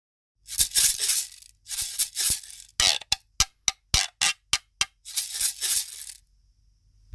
マダガスカル産の竹でできたギロのような楽器です。溝をスティックでこすり、ごきげんなサウンドを奏でます。
素材： 竹